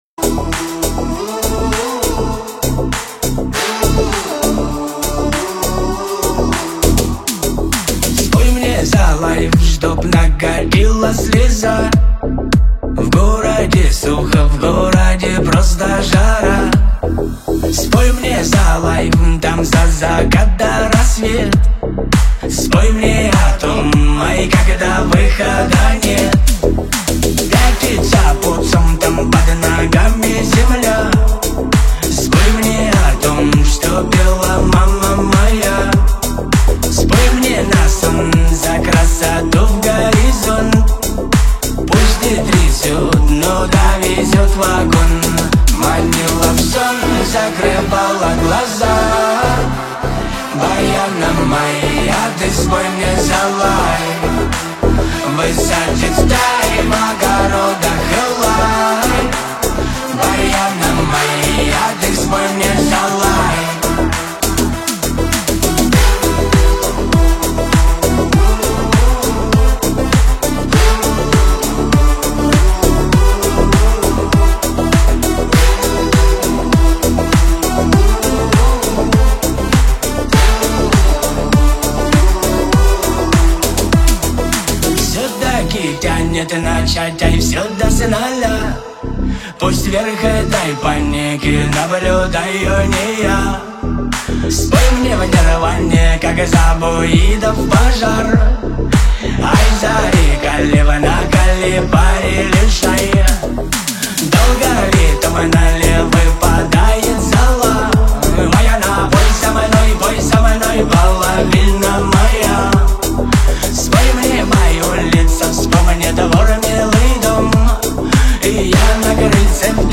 Танцевальная музыка
Dance музыка